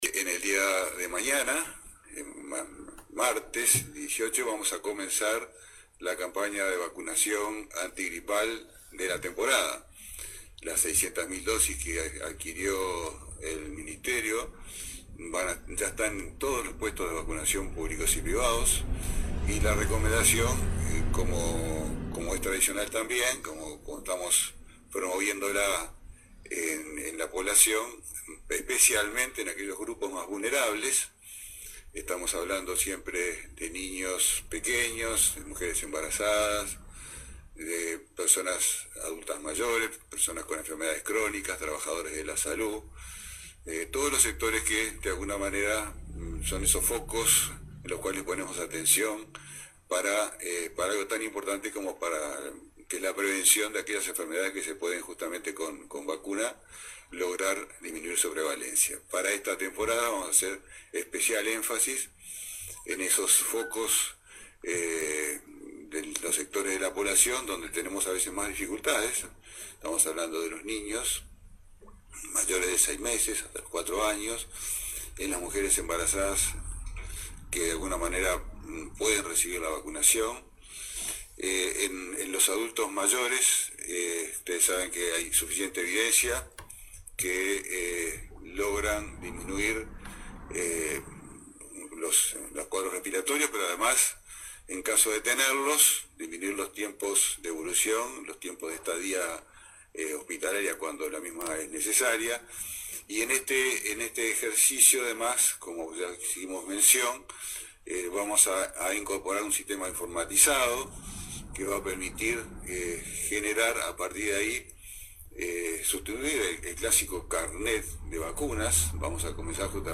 El ministro de Salud Pública, Jorge Basso, confirmó que este martes comienza la campaña de vacunación antigripal, que este año pondrá el foco en los niños de entre seis meses y cuatro años, embarazadas y adultos mayores. Al término del Consejo de Ministros de este lunes, Basso dio detalles de la campaña antigripal 2017 y adelantó que con esta campaña se iniciará la informatización del sistema de vacunas.